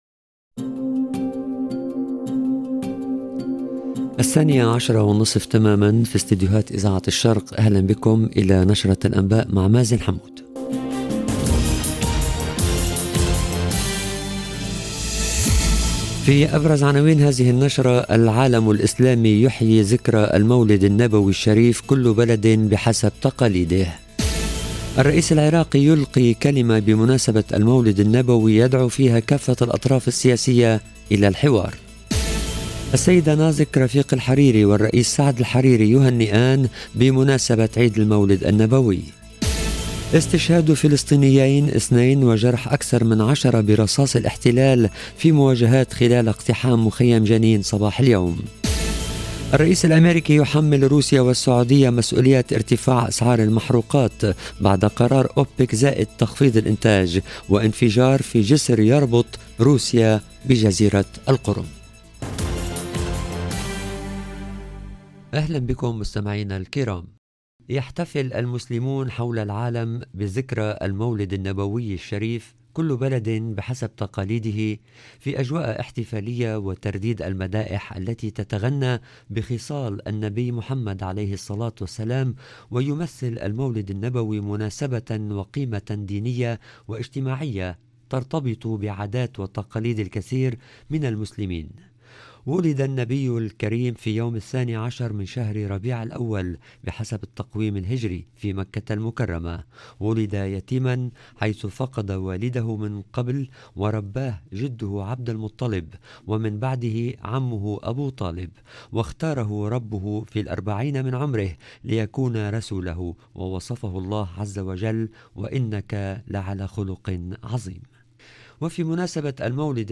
LE JOURNAL EN LANGUE ARABE DE MIDI 30 DU 8/10/22